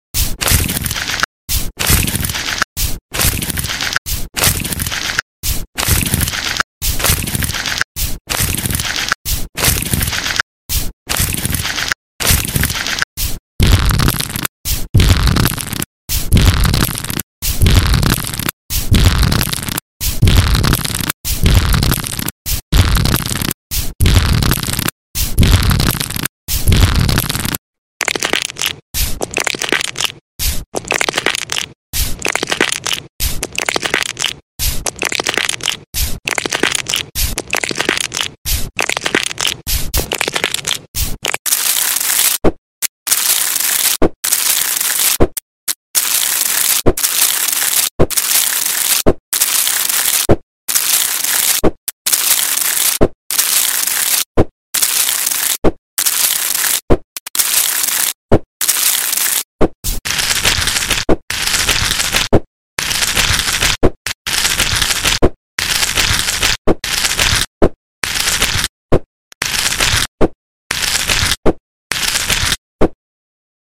ASMR sleep anducing foot massage sound effects free download
ASMR sleep anducing foot massage for calming | ASMR ultimate spa relaxation